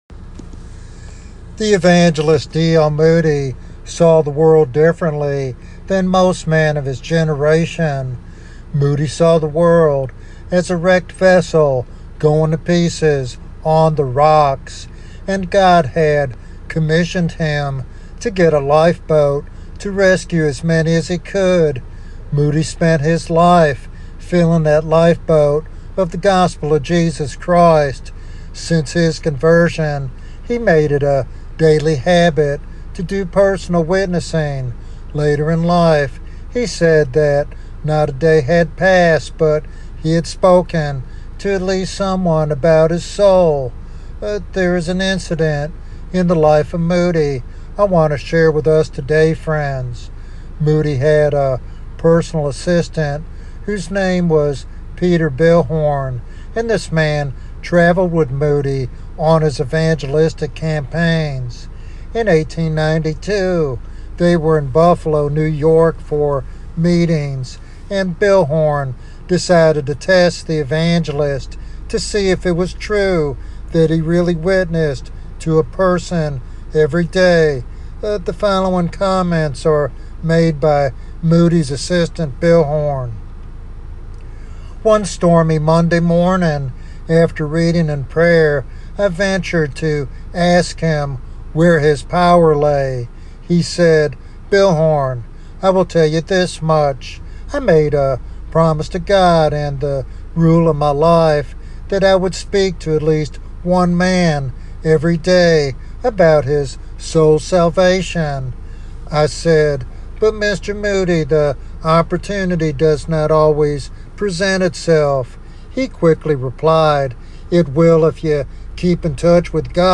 The sermon serves as both an inspiration and a call to action for believers to actively engage in evangelism.
Sermon Outline